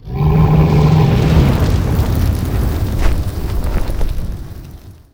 bellow.wav